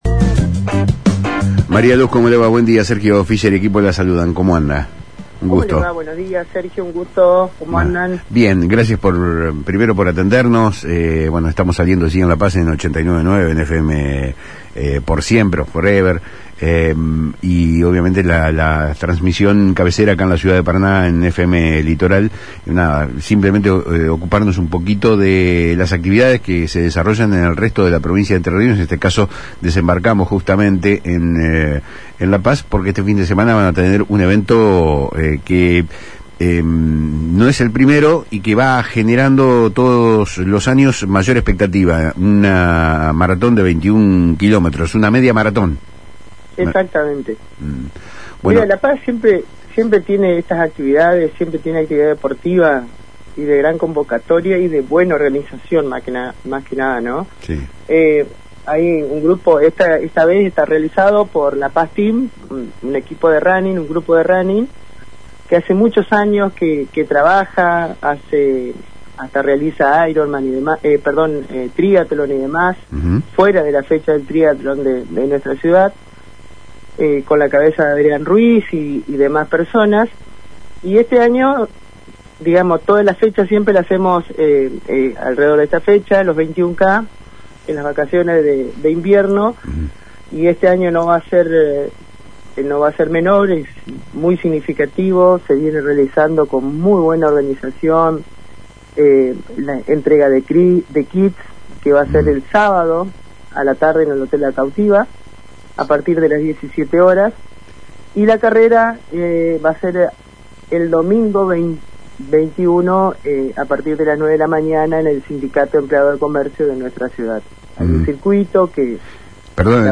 Detalles del evento La Secretaria de Turismo, Deportes y Cultura del Municipio de La Paz, María Luz De Bernardo, brindó detalles organizativos y de realización del evento en diálogo con Palabras Cruzadas de FM Litoral.